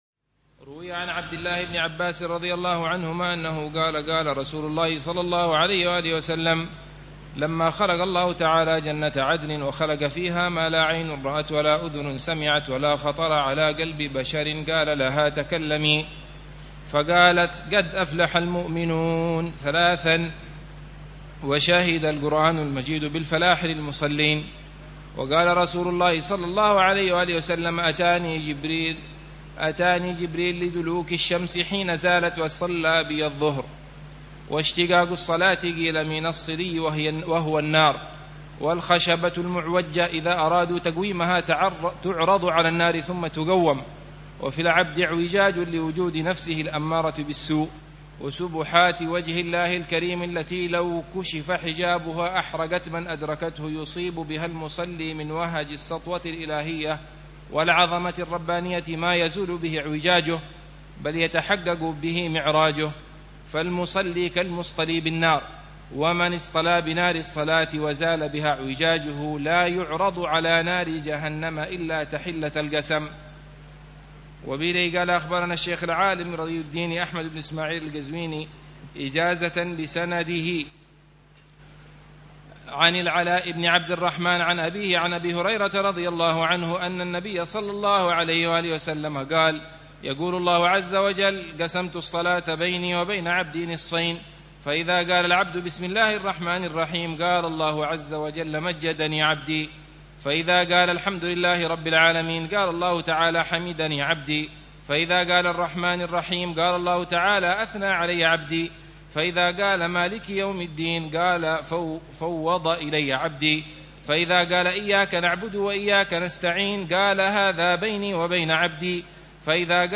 شرح كتاب عوارف المعارف - الدرس الثامن والأربعون - فضيلة الصلاة وكبر شأنها
شرح لكتاب عوارف المعارف للإمام السهروردي ضمن دروس الدورة التعليمية الثانية عشرة والثالثة عشرة بدار المصطفى في صيف عامي 1427هـ و